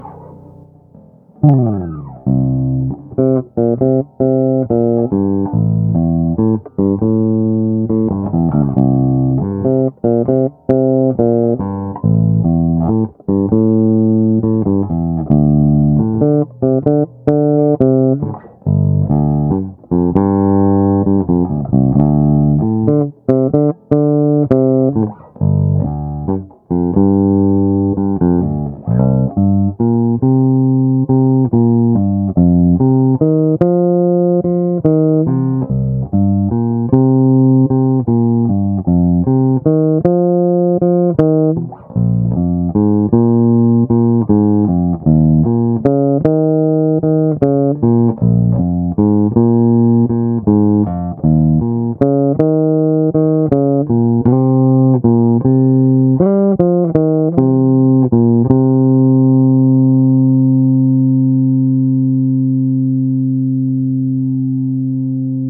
Udělal jsem i první nahrávku, takže zvuk můžete posoudit sami - oba poťáky naplno, rovnou do vzukovky, bez úprav.